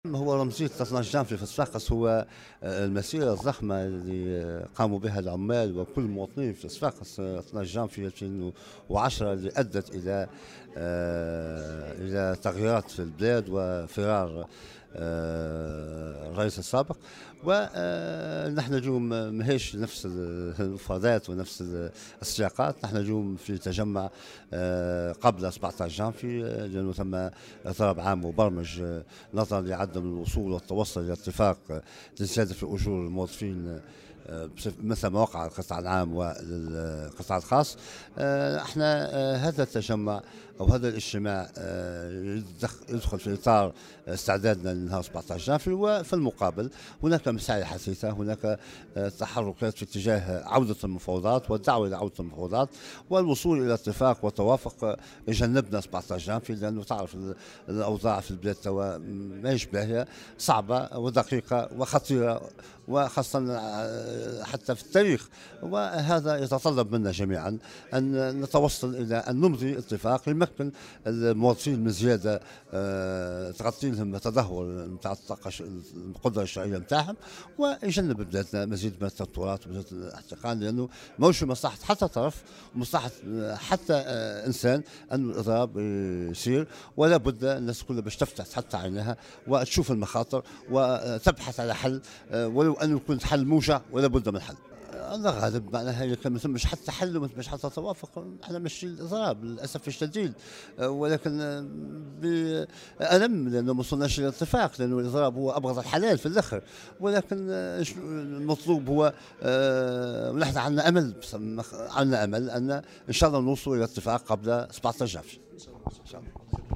وقال في تصريح لمراسل "الجوهرة أف أم" على هامش تجمّع عمالي للوظيفة العمومية والقطاع العام انتظم بدار الاتحاد الجهوي للشغل بصفاقس استعدادا لهذا الاضراب، إن هناك تحركات في اتّجاه الدّعوة لمفاوضات والتوصّل لاتفاق يجنّب البلاد اضراب 17 جانفي. ونبّه في ذات السياق إلى وضع البلاد الصعب والخطير والذي يتطلب التوصل لاتفاق يمكّن الموظفين من زيادة في أجورهم لتغطية تدهور قدرتهم الشرائية.